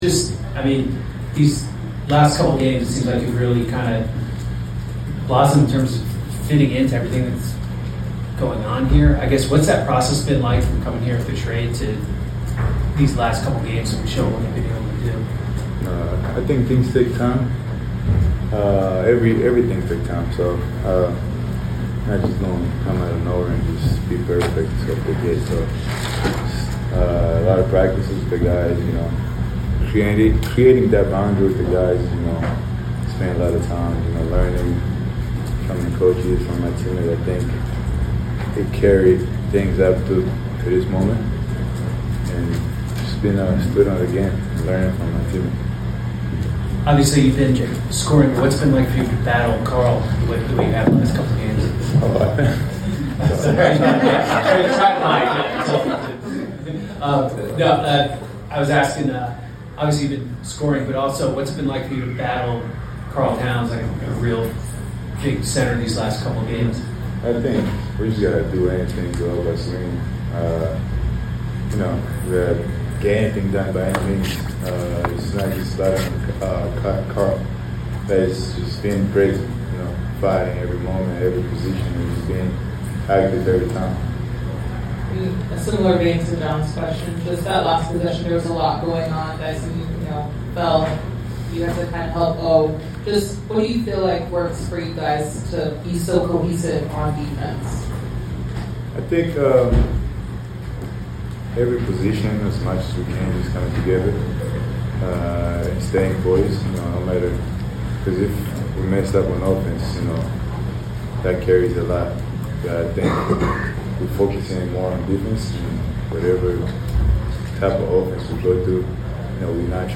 04-23-26 Atlanta Hawks Forward Jonathan Kuminga Postgame Interview
Atlanta Hawks Forward Jonathan Kuminga Postgame Interview before taking on the New York Knicks at State Farm Arena.